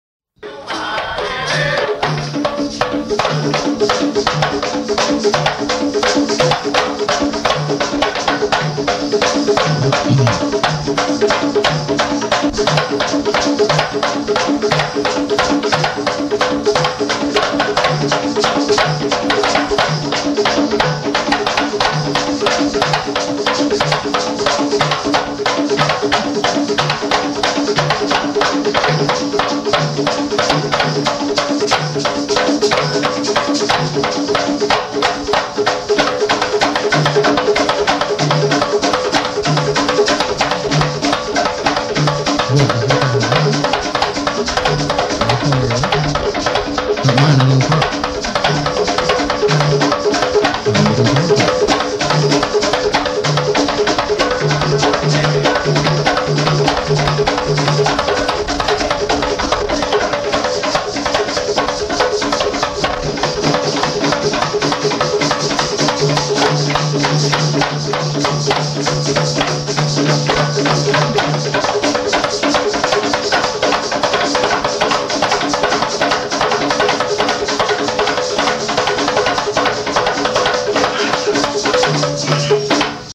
Afro-Cuban Percussion Sample